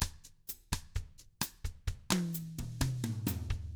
129BOSSAF2-R.wav